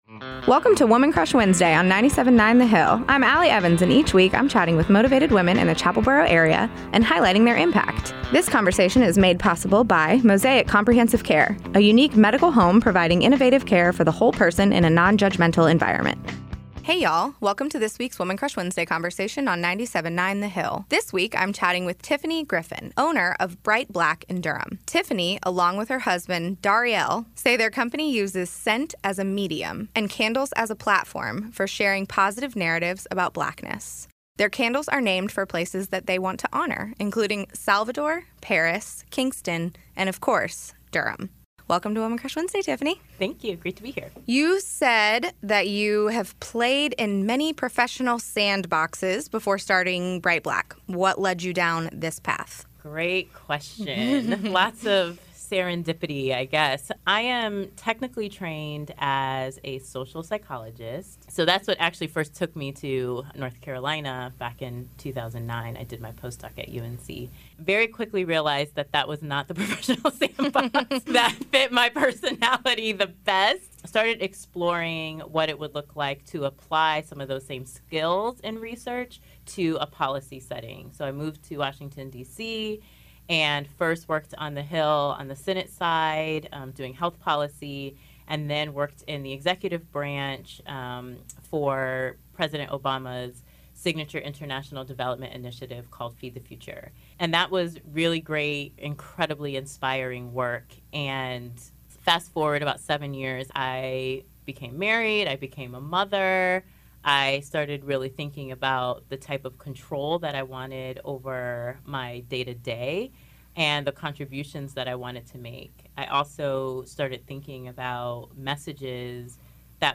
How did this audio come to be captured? an extended version of what aired on 97.9 The Hill!